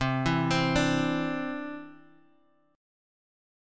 Cadd9 Chord
Listen to Cadd9 strummed